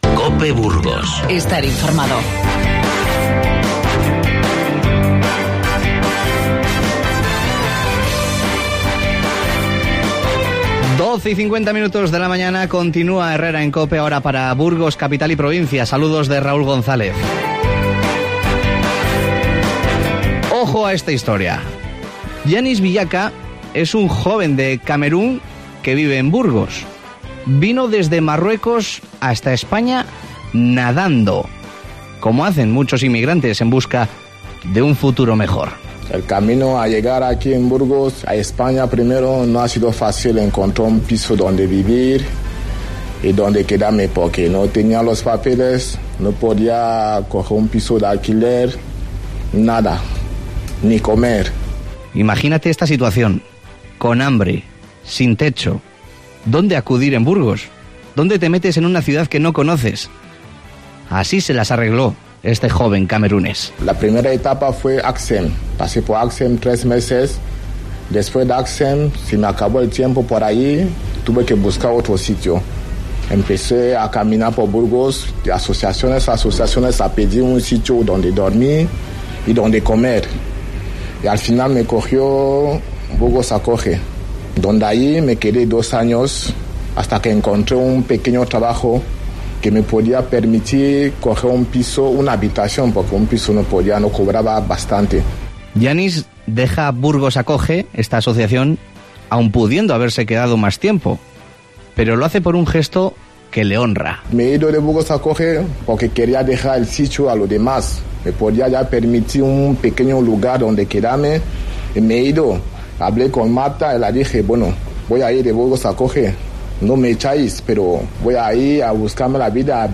Escucha la historia de este camerunés, con su propia voz, en el Herrera en COPE Burgos de hoy: